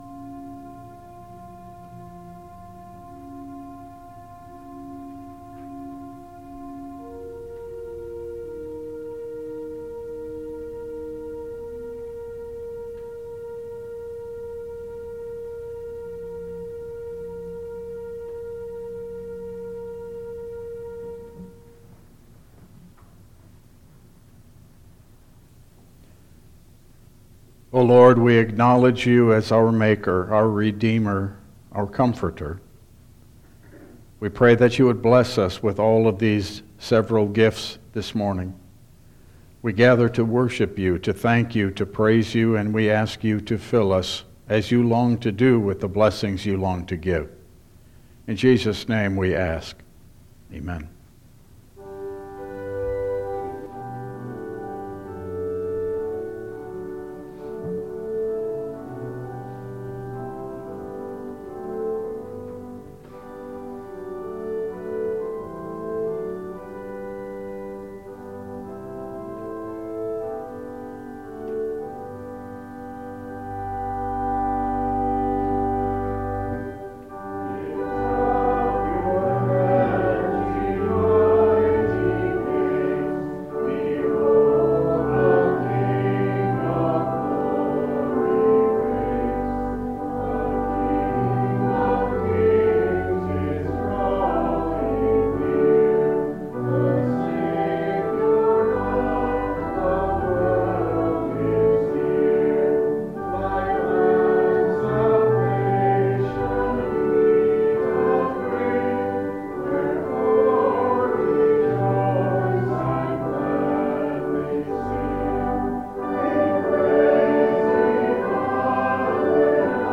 Download Files Printed Sermon and Bulletin